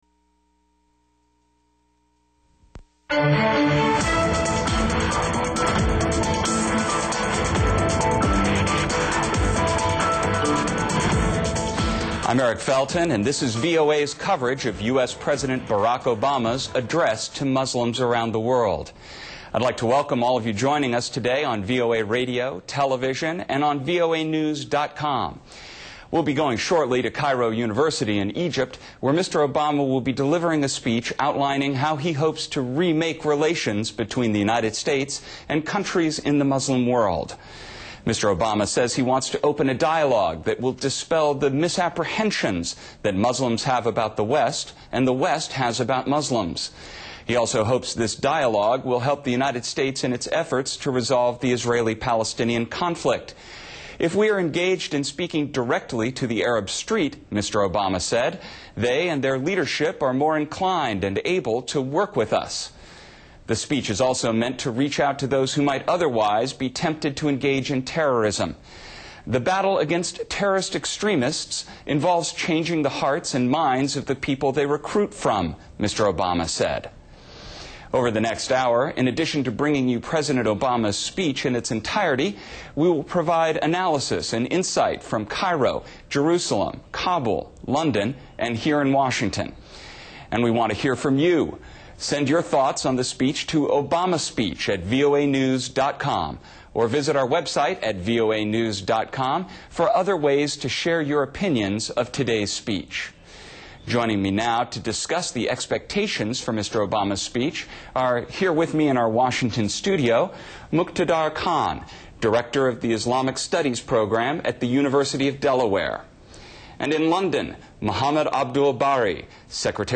(VOA Program including speech)
Obama_-_Cairo_Speech-e.mp3